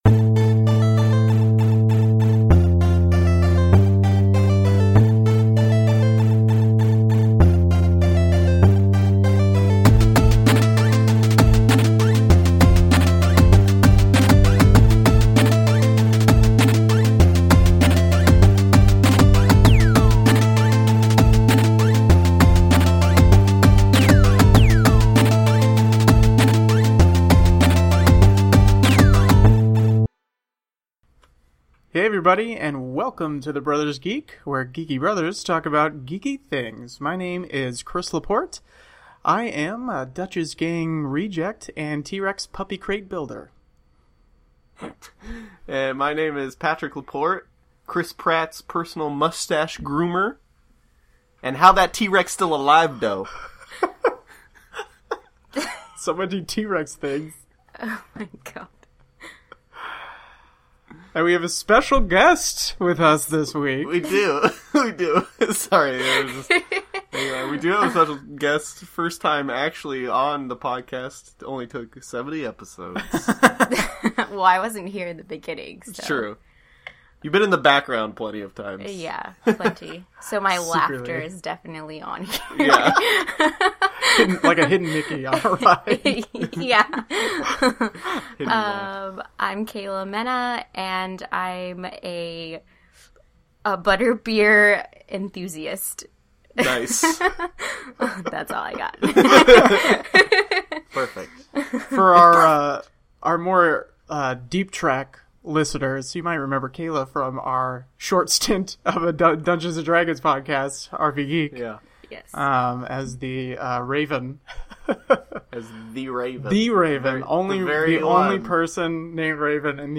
They discuss topics relating to video games, movies and television all while drinking some good beer and laughing plenty.